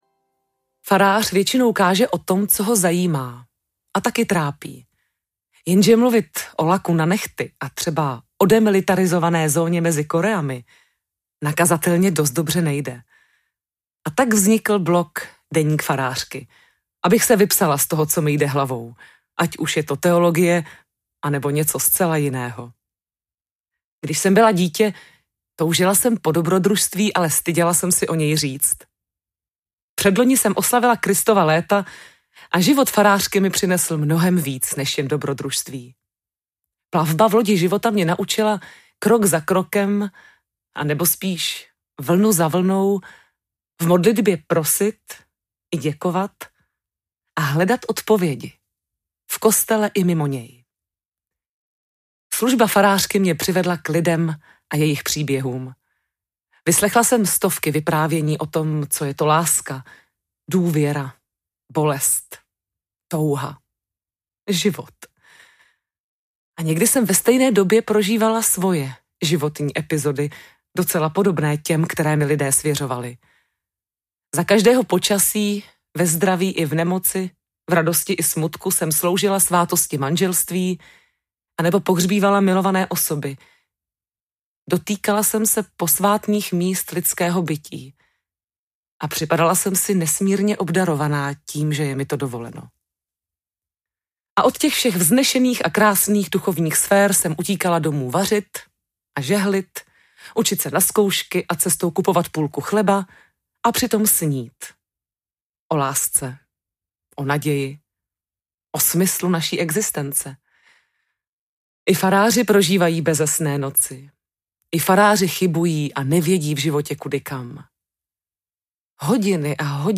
Audiokniha
stáhnout ukázku